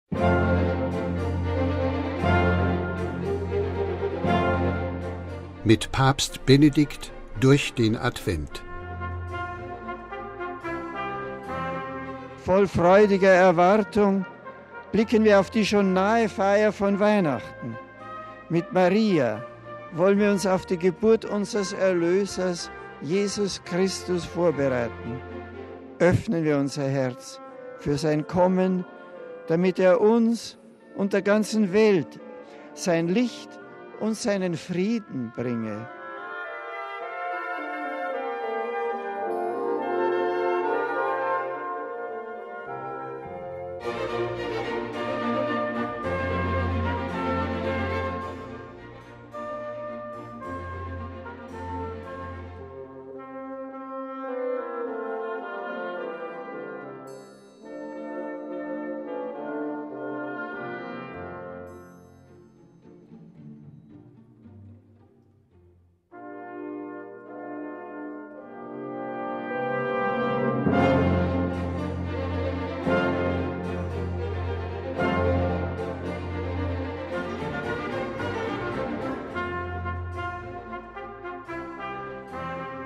MP3 Besinnlich durch den Advent: Jeden Tag in der Vorweihnachtszeit finden Sie in unserem Audio-Adventskalender einige meditative Worte von Papst Benedikt XVI.
(Benedikt XVI.; aus dem Angelus vom 24.12.2006)